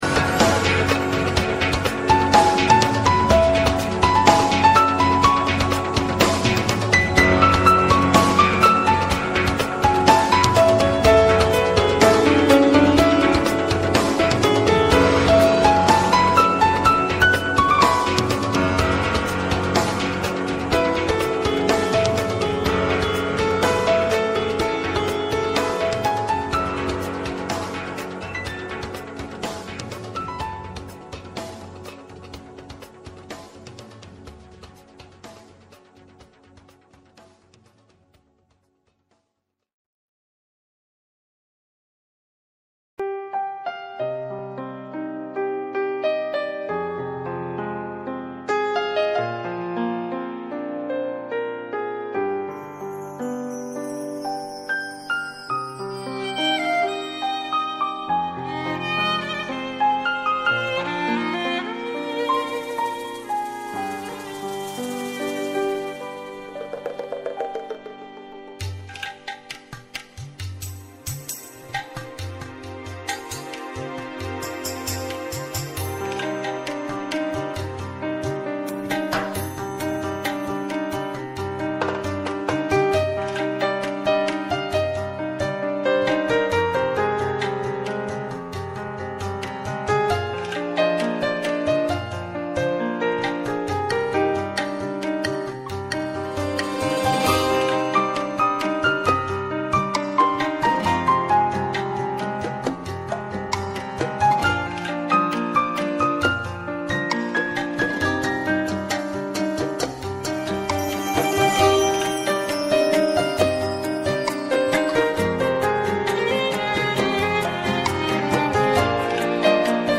مجمع عمومی عادی بطور فوق العاده شرکت صنعتی و کشاورزی شیرین خراسان - نماد: قشرین